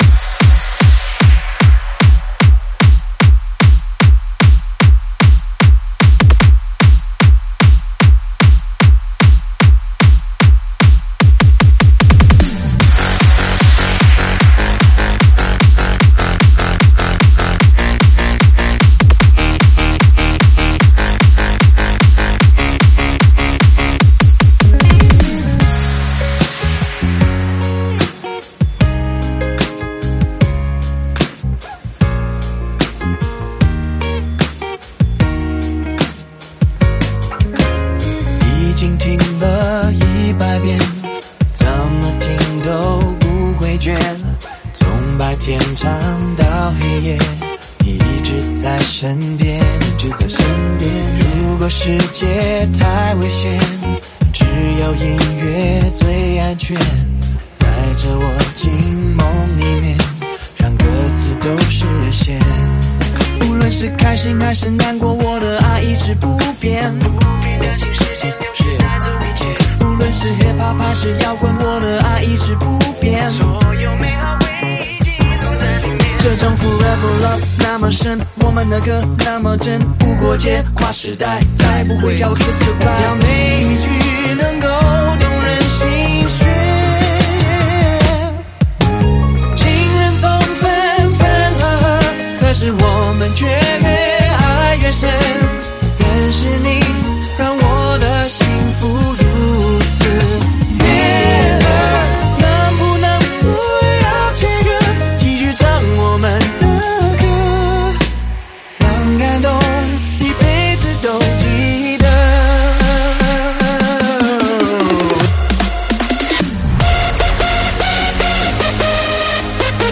试听文件为低音质，下载后为无水印高音质文件 M币 4 超级会员 M币 2 购买下载 您当前未登录！